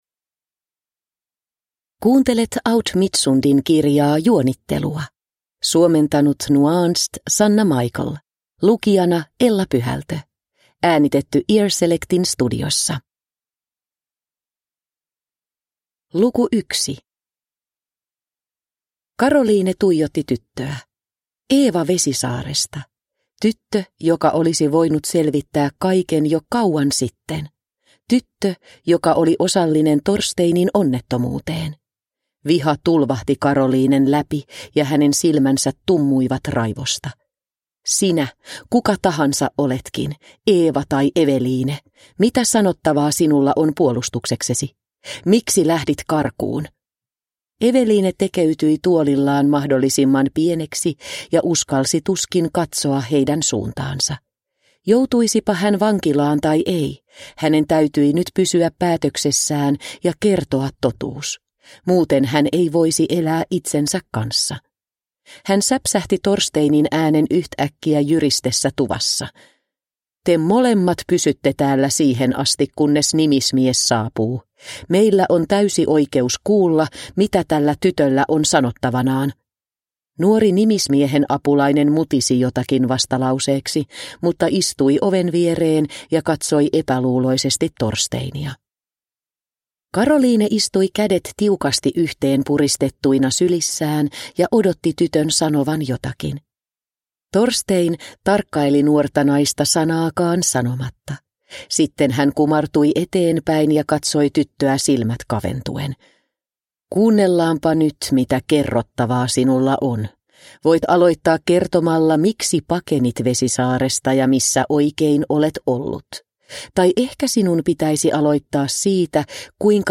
Juonittelua – Ljudbok – Laddas ner